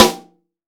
Index of /90_sSampleCDs/AKAI S6000 CD-ROM - Volume 3/Snare1/PICCOLO_SN